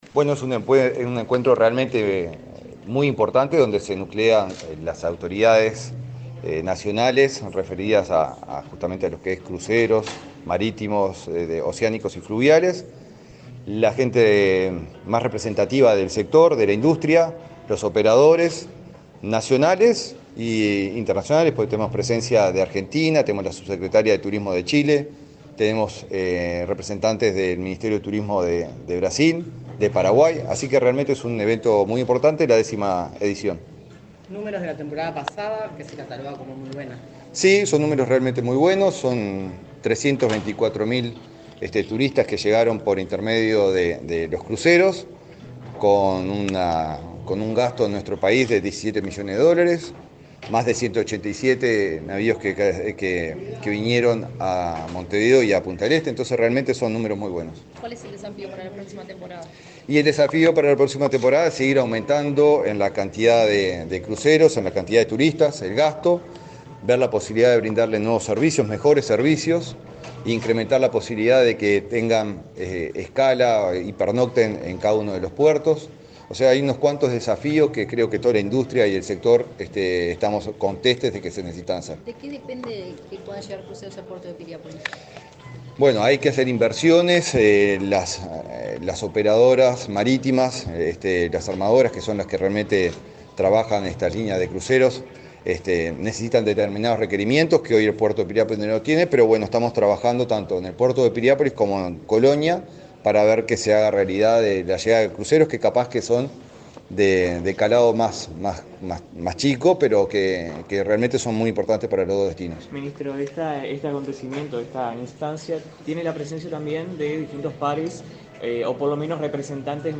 Declaraciones del ministro de Turismo, Eduardo Sanguinetti
Declaraciones del ministro de Turismo, Eduardo Sanguinetti 01/08/2024 Compartir Facebook X Copiar enlace WhatsApp LinkedIn El ministro de Turismo, Eduardo Sanguinetti, dialogó con la prensa, durante el X Encuentro Regional de Cruceros y Turismo Náutico Fluvial, realizado este jueves 1.° en Punta del Este, departamento de Maldonado.